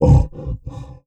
MONSTER_Effort_06_mono.wav